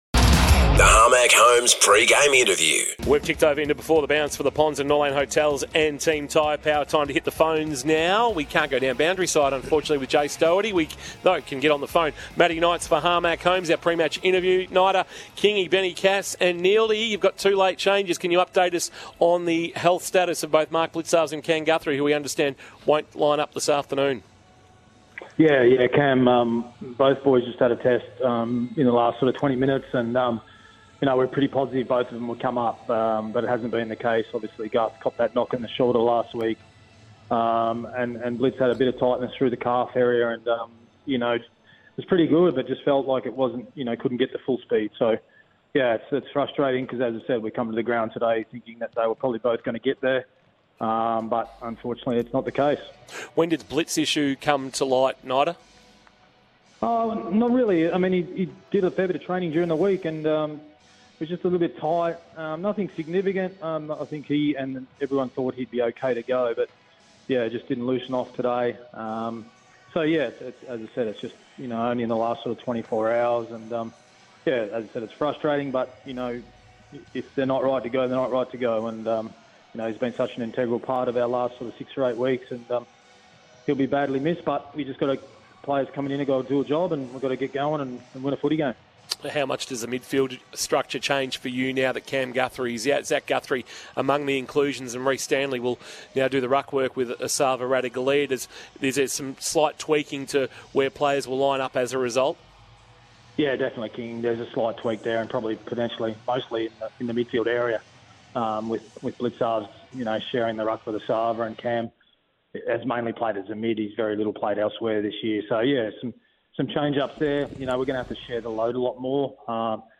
PRE-MATCH INTERVIEW: MATTHEW KNIGHTS - Geelong Assistant